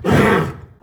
CosmicRageSounds / wav / general / combat / creatures / horse / he / attack1.wav